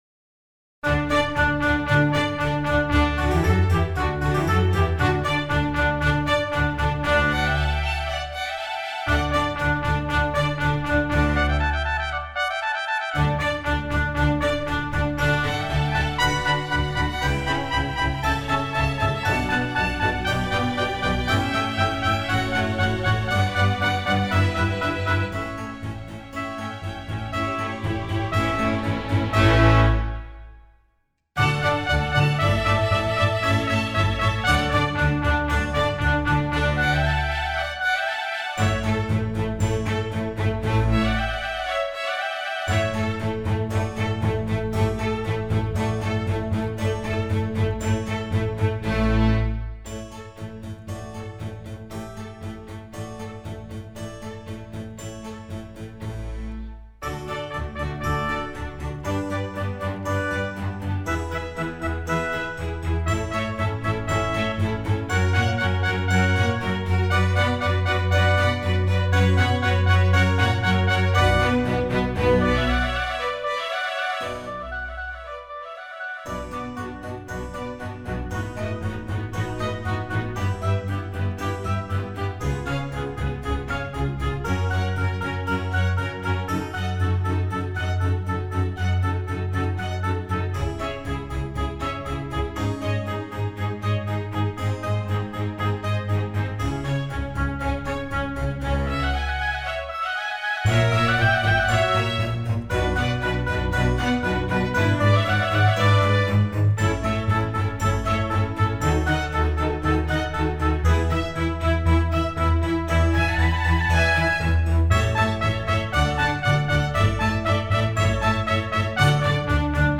Genre: Classical.